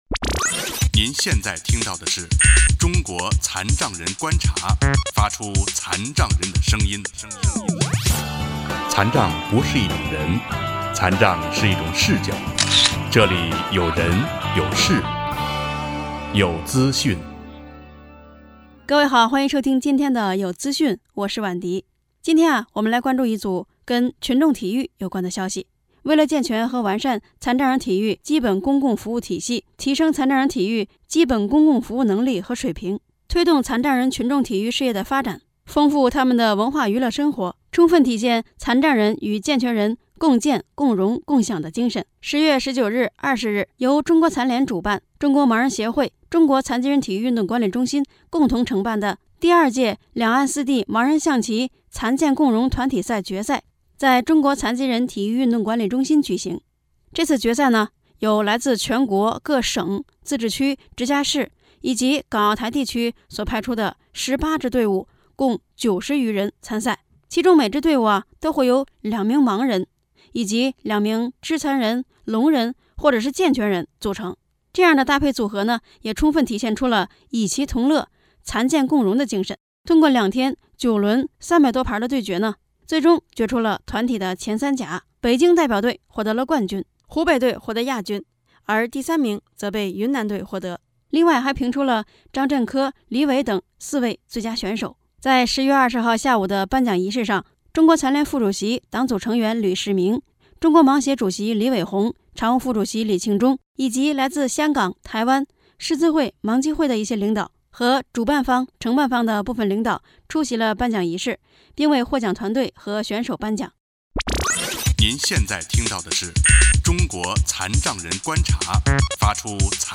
下面是《有资讯》对此次盲人象棋赛事的报道